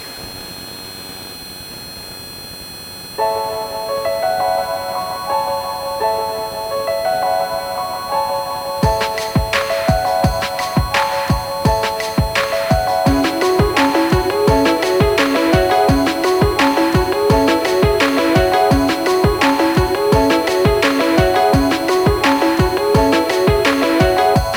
piano_noisy.mp3